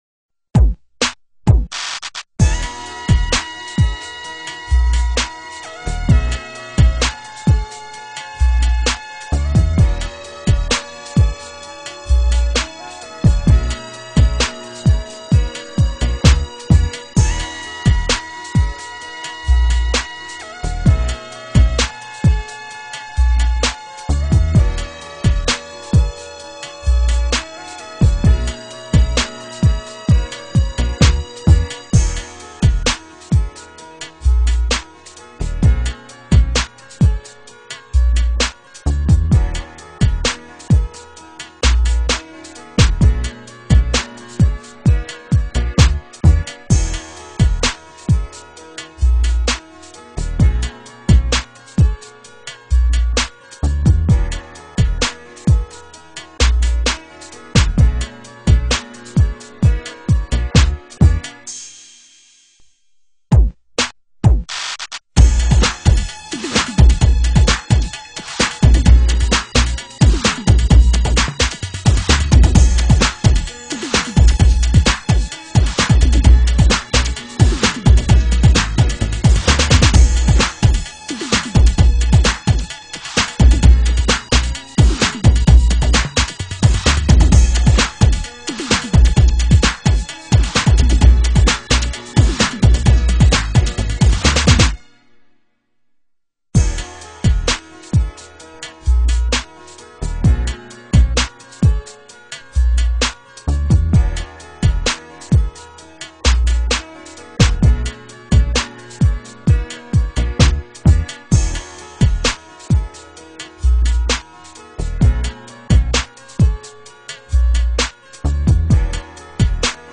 This is the official instrumental